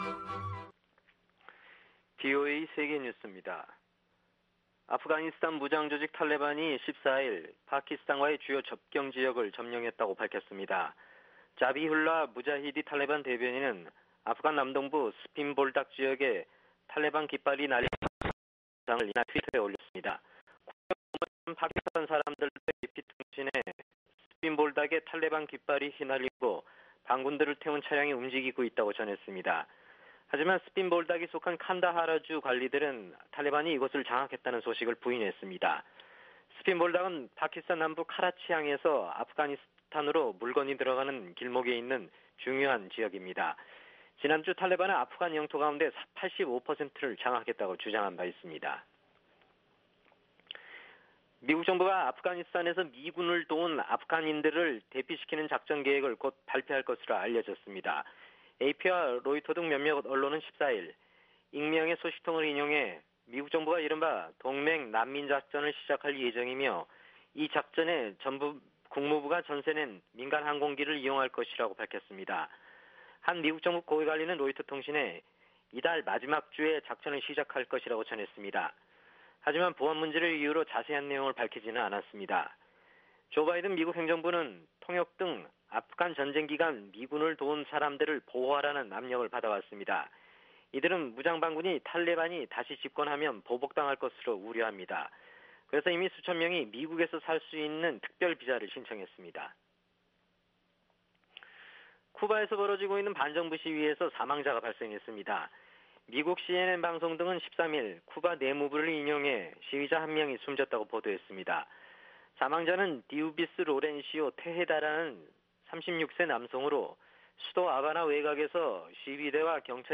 VOA 한국어 아침 뉴스 프로그램 '워싱턴 뉴스 광장' 2021년 7월 15일 방송입니다. 북한 만수대창작사가 위장회사를 내세워 아프리카 베냉에 대형 동상을 제작 중인 것으로 확인됐습니다. 미국은 북한과 신종 코로나바이러스 ‘백신 외교’로 대화를 재개할 기회가 있다고, 민주당 아미 베라 하원의원이 밝혔습니다. 북한이 탄도미사일과 핵 개발 역량을 계속 진전시키고 있다고 일본 정부가 연례 방위백서에서 밝혔습니다.